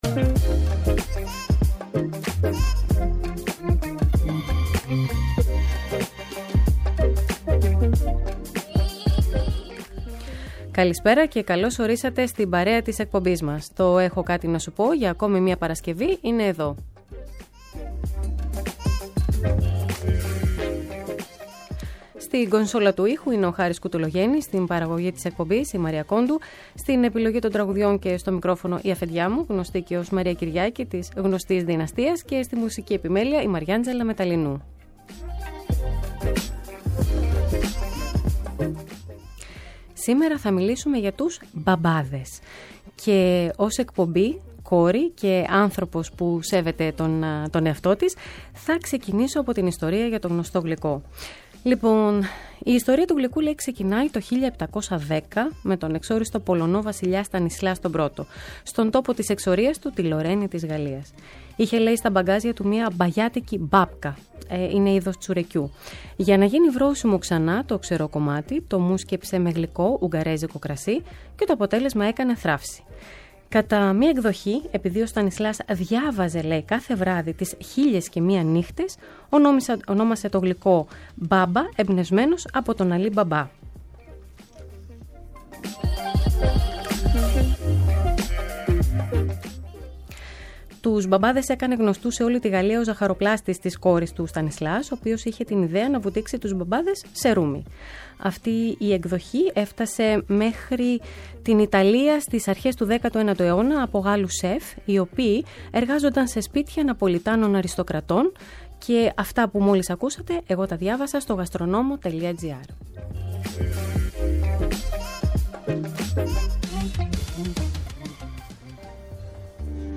Κάθε Παρασκευή 7 με 8 το βράδυ, ένα τραγούδι γίνεται οδηγός για το κεντρικό θέμα σε κάθε εκπομπή. Για το ευ στο ζην, από συναισθήματα και εμπειρίες μέχρι πεποιθήσεις που μας κάνουν να δυσλειτουργούμε ή να κινητοποιούμαστε, έχουν κάτι να μας πουν ειδικοί σε επικοινωνία με ακροατές.